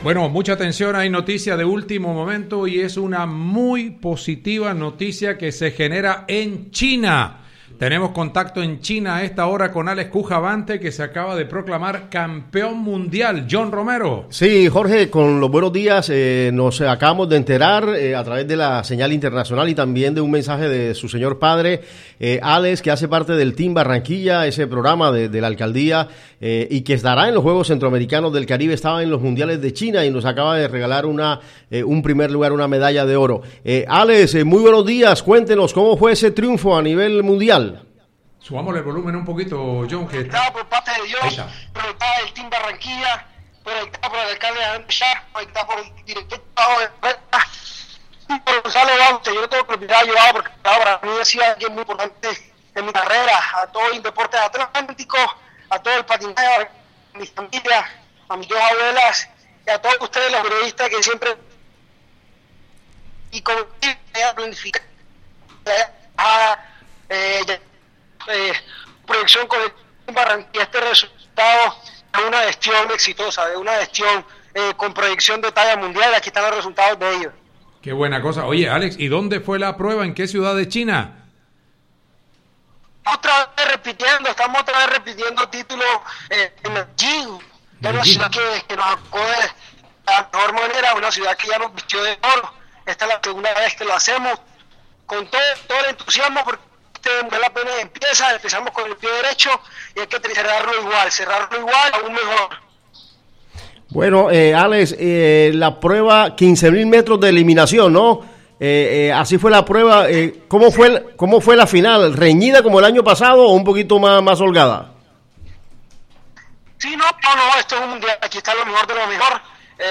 En diálogo con Emisora Atlántico desde China, Cujavante se mostró feliz con este nuevo logro en su carrera y agradeció el respaldo de la administración distrital, a través del Team Barranquilla.
A su turno, el alcalde Alejandro Char expresó estar muy contento con el desempeño de Cujavante y los logros que ha obtenido dejando en alto el nombre de la ciudad.